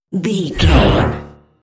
Sci fi electronic whoosh
Sound Effects
Atonal
futuristic
high tech
intense